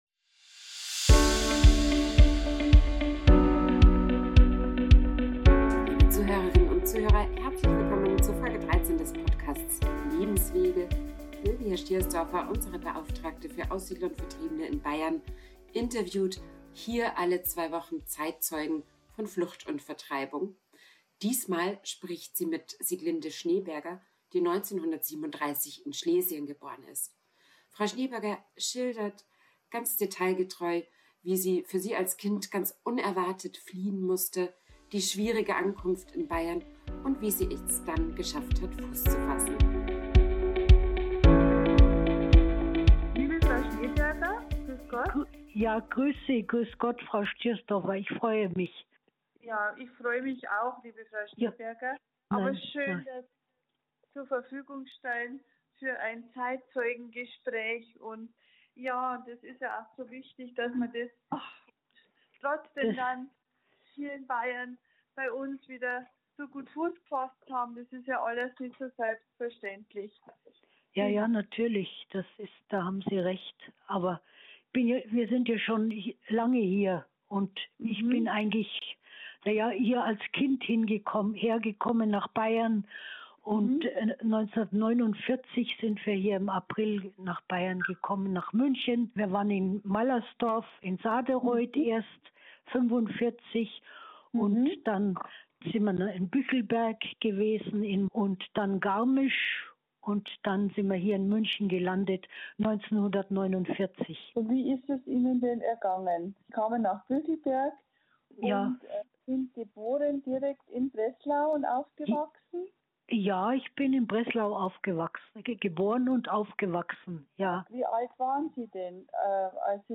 Zeitzeugengespräche